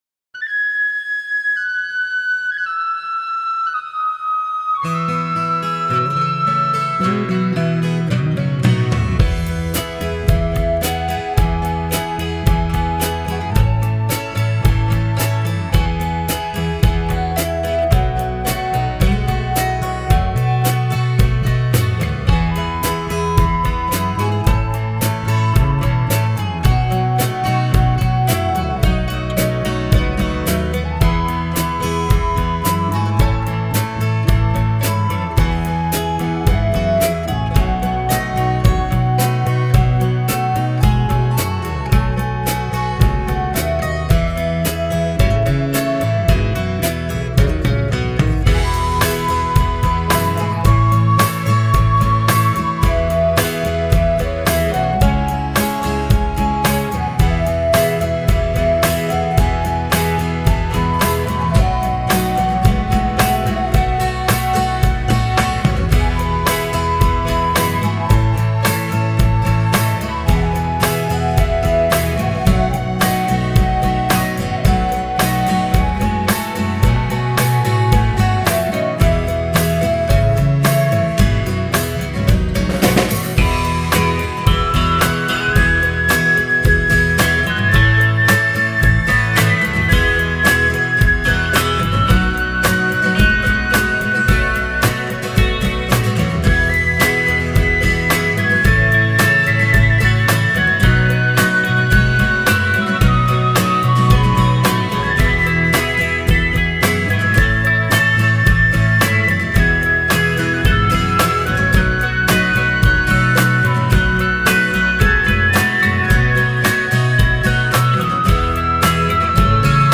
Free MP3 backing track samples